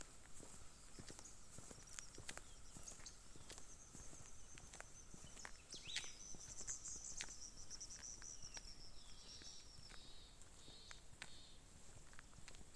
Andean Swift (Aeronautes andecolus)
Location or protected area: Amaicha del Valle
Condition: Wild
Certainty: Observed, Recorded vocal